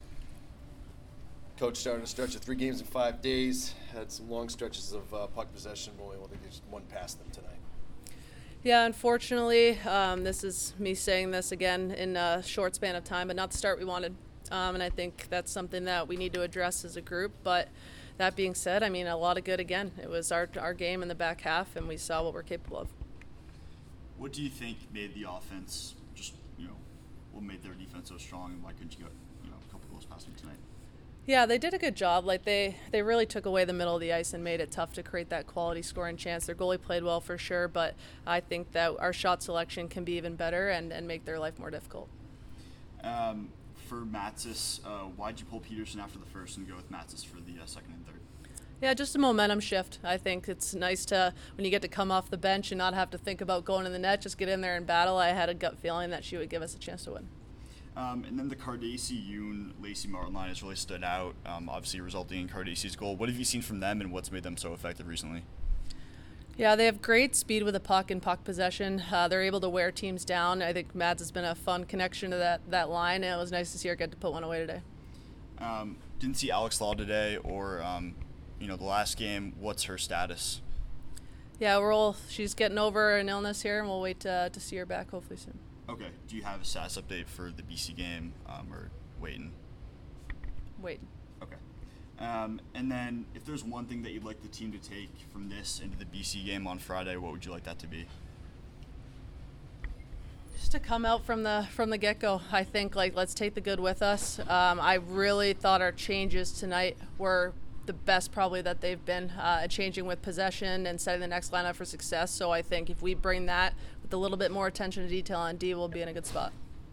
Women's Ice Hockey / Harvard Postgame Interview (11-14-23)